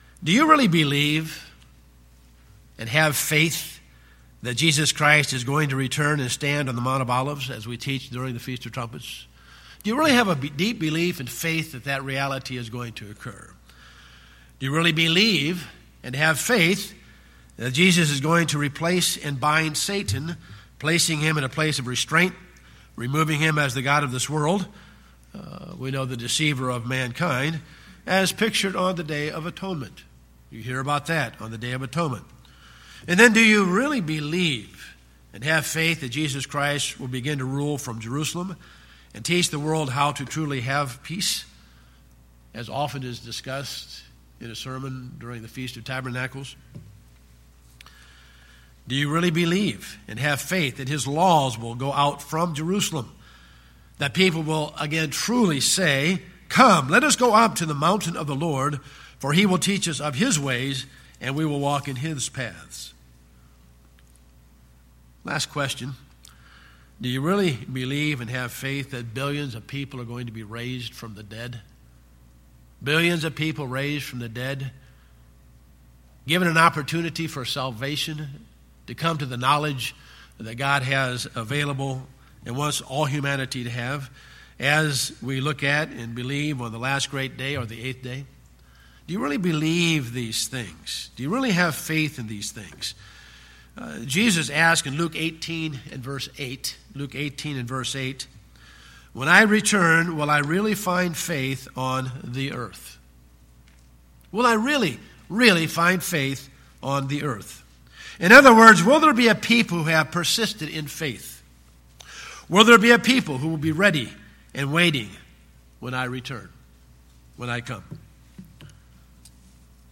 Learn in this sermon how to develop and maintain your faith.